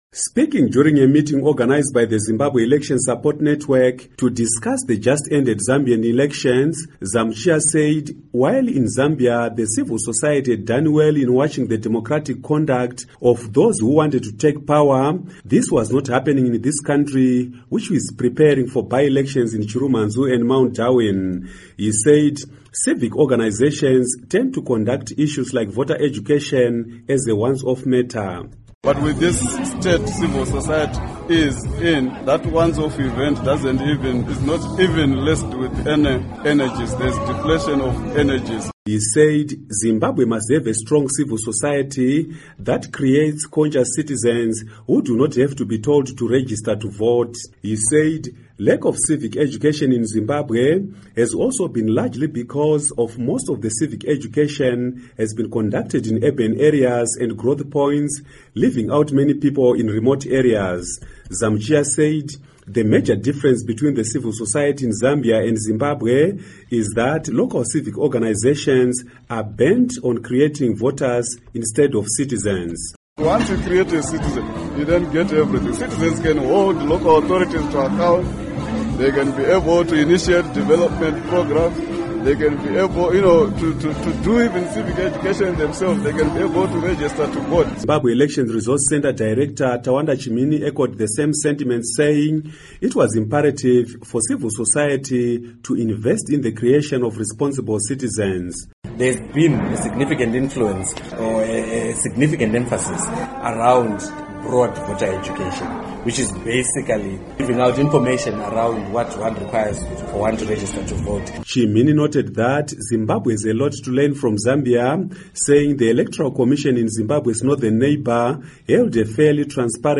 Report on CIvil Society, Zambia Elections